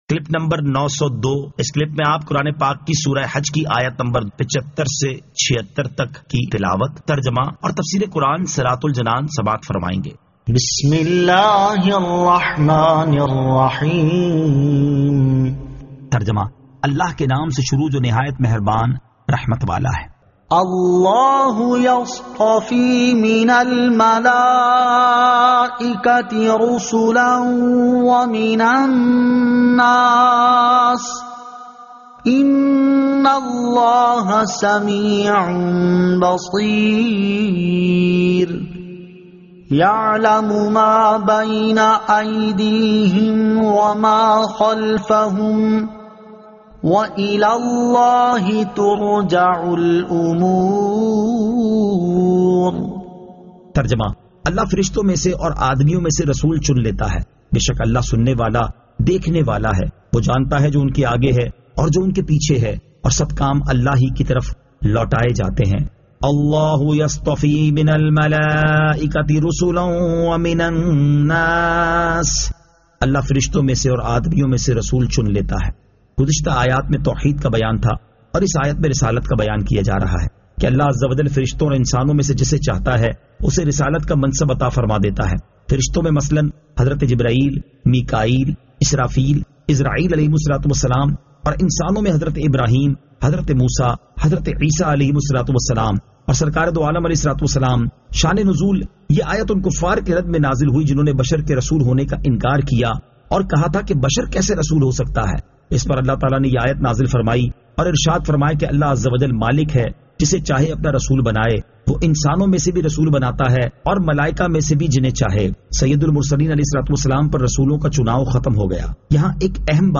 Surah Al-Hajj 75 To 76 Tilawat , Tarjama , Tafseer